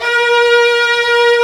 Index of /90_sSampleCDs/Roland L-CD702/VOL-1/CMB_Combos 2/CMB_Hi Strings 3
STR VIOLIN01.wav